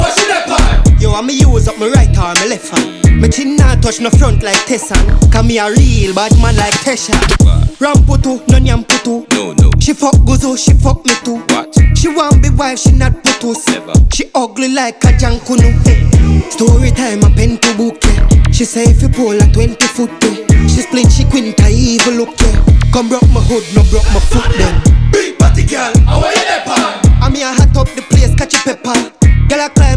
Off-beat гитары и расслабленный ритм
Жанр: Регги